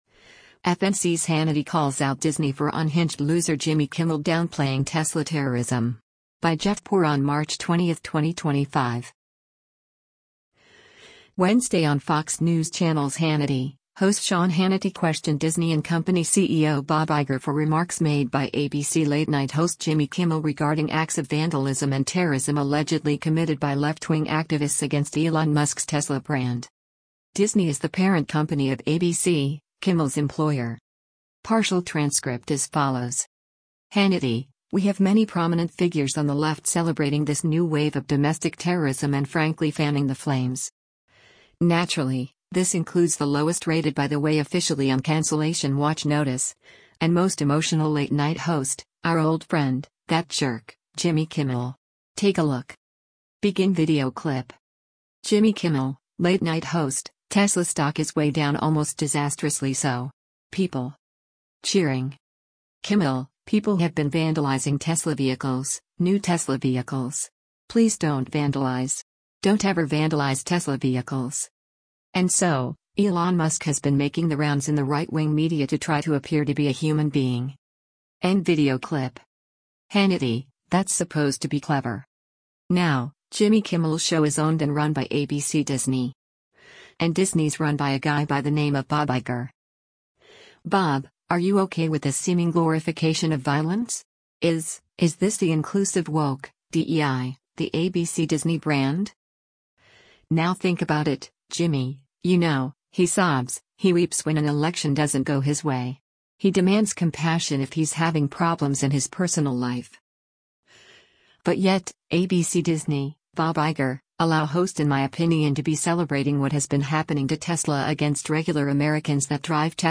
Wednesday on Fox News Channel’s “Hannity,” host Sean Hannity questioned Disney and company CEO Bob Iger for remarks made by ABC late-night host Jimmy Kimmel regarding acts of vandalism and terrorism allegedly committed by left-wing activists against Elon Musk’s Tesla brand.